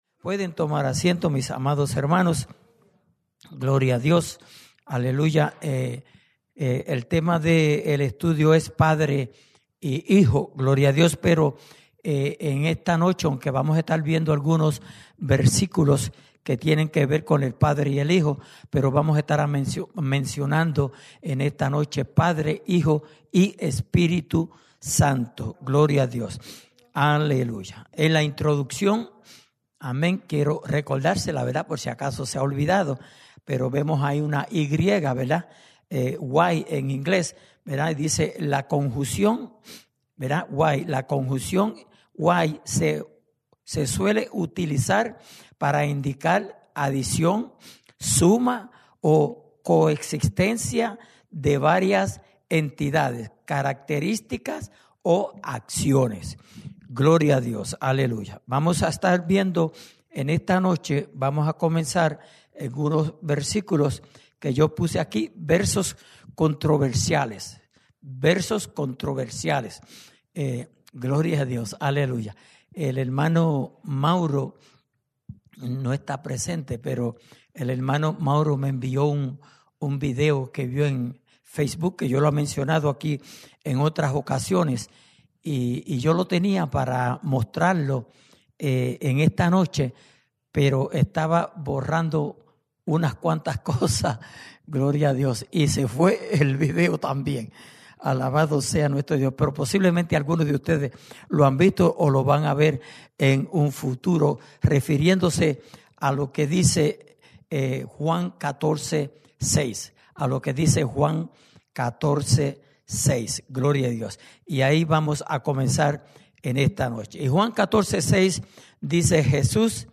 Estudio Bíblico: Padre Y Hijo (Quinta Parte)